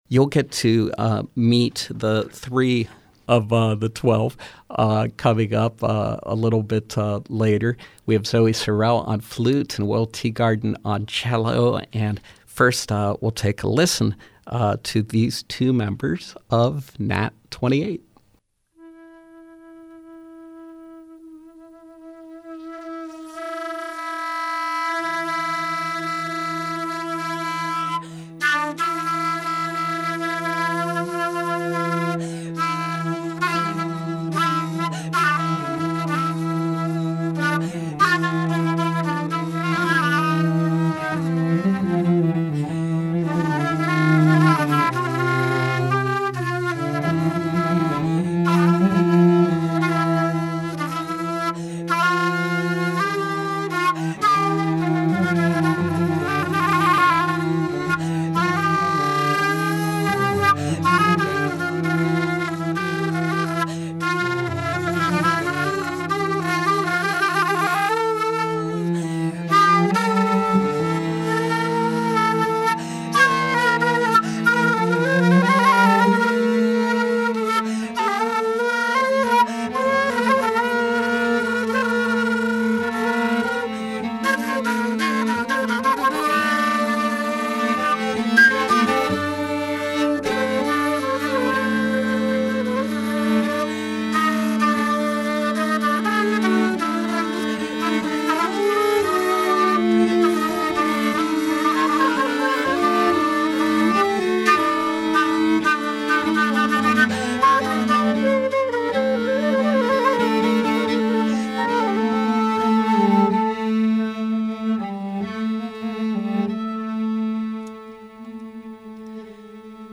flute
cello
contemporary music ensemble
music based on dance traditions from around the world